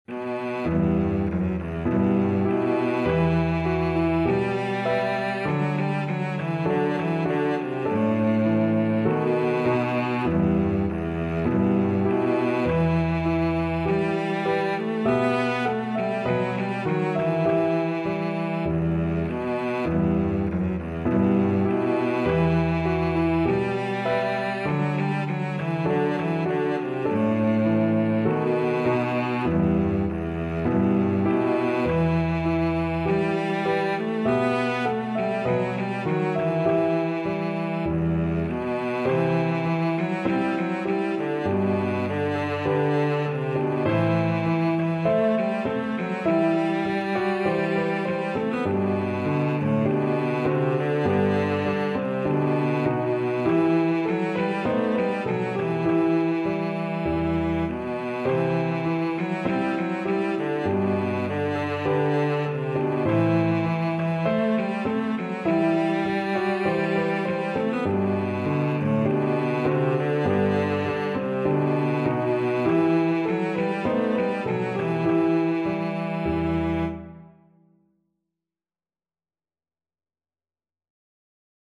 Cello
Traditional Music of unknown author.
E minor (Sounding Pitch) (View more E minor Music for Cello )
E3-B4
Steady two in a bar = c.50